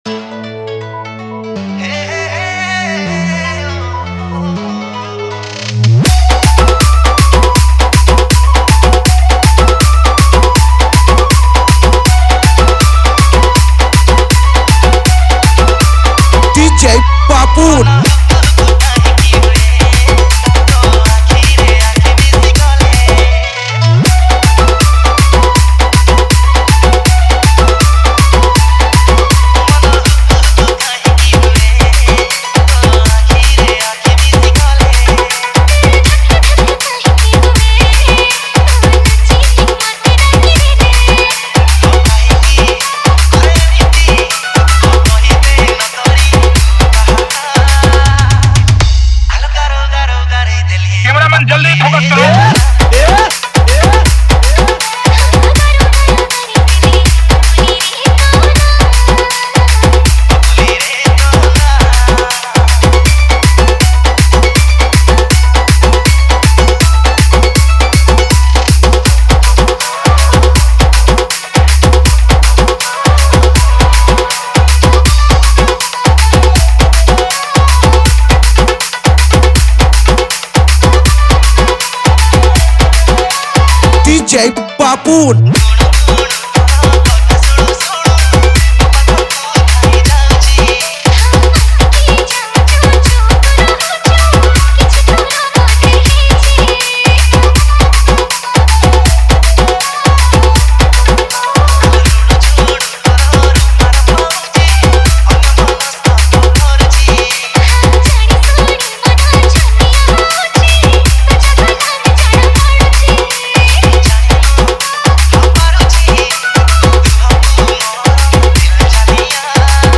Category:  New Odia Dj Song 2024